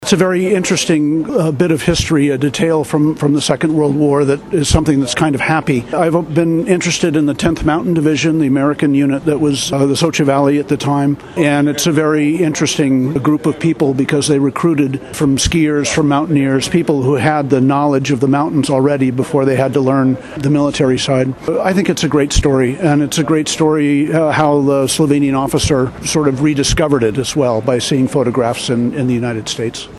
izjavaveleposlanikazdavslovenijin.e.brentarobertahartleya.mp3 (725kB)
75046_izjavaveleposlanikazdavslovenijin.e.brentarobertahartleya.mp3